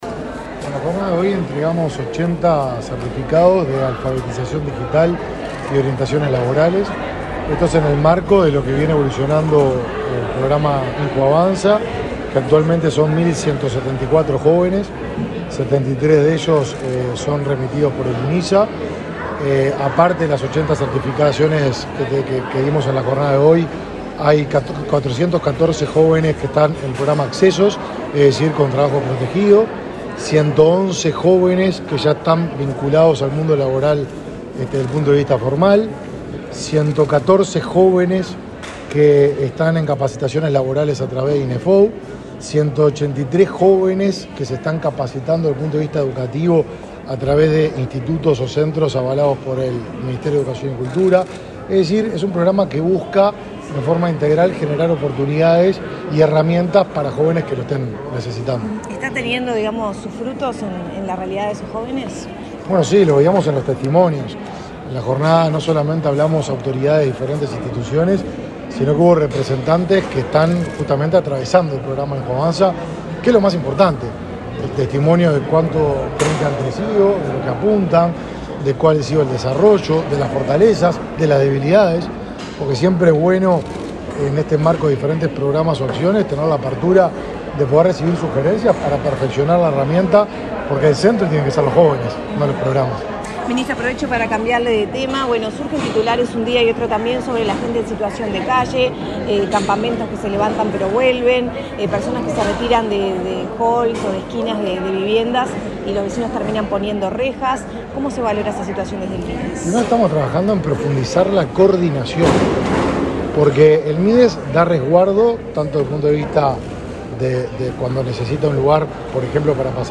Declaraciones del ministro de Desarrollo Social, Martín Lema
Declaraciones del ministro de Desarrollo Social, Martín Lema 29/08/2022 Compartir Facebook X Copiar enlace WhatsApp LinkedIn El ministro de Desarrollo Social, Martín Lema, participó de la entrega de certificados a 80 jóvenes de Montevideo y área metropolitana que participaron de talleres de orientación laboral y alfabetización digital, en el marco del programa INJU Avanza. Luego, dialogó con la prensa.